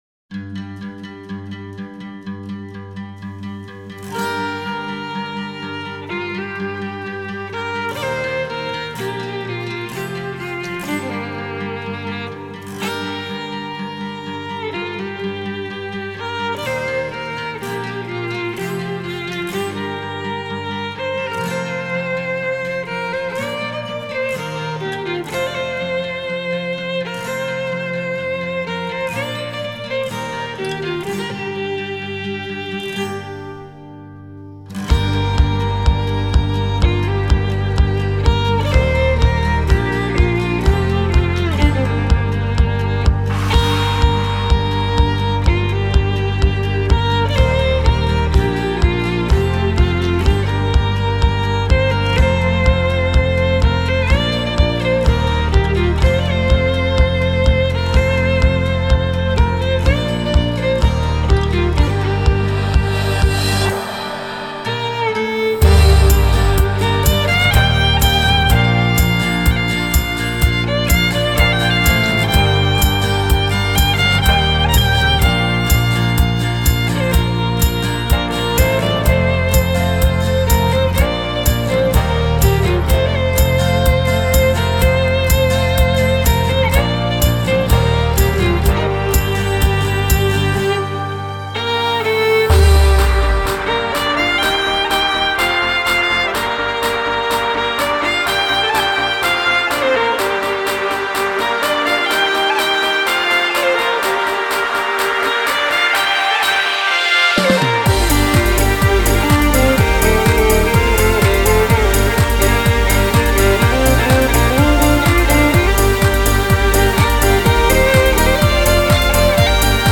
violin cover